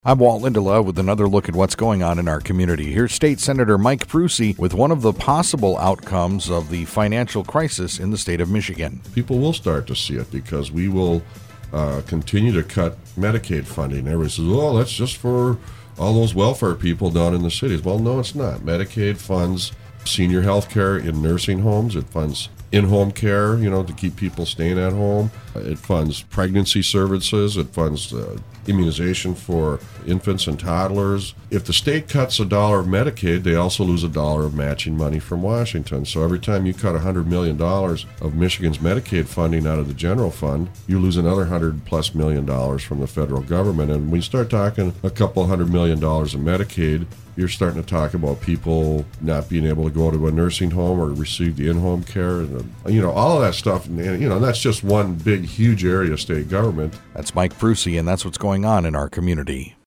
Click HERE To Listen To Interview as Mp3 Click To Submit Press Releases, News, Calendar Items, and Community Events to mediaBrew radio stations WFXD, WKQS, WRUP, GTO, Fox Sport Marquette, and 106.1 The SoundMarquette,...